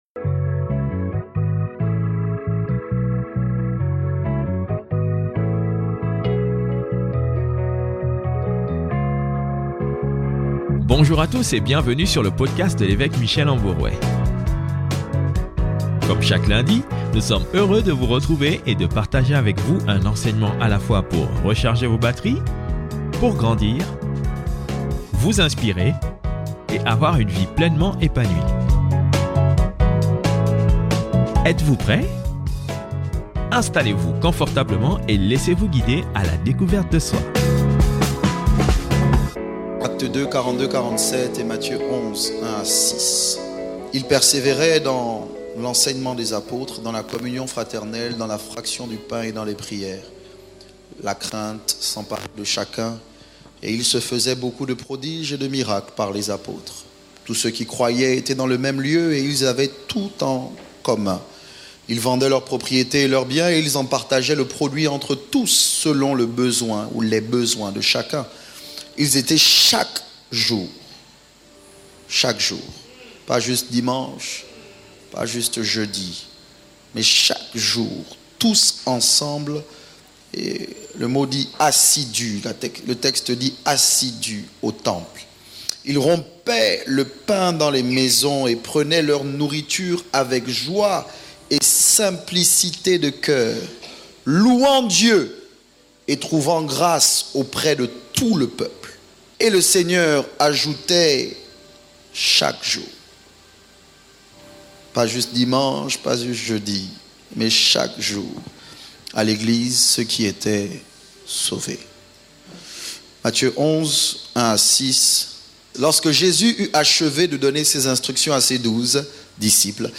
Headliner Embed Embed code See more options Share Facebook X Subscribe L'enseignement, la communion, la prière et la fraction du pain sont des éléments essentiels au miracle, à la richesse, au partage et à la vie chrétienne. Dans cet enseignement, j'aimerais vous emmener à comprendre qu'il faut persévérer chaque jour afin de rester uni chaque jour.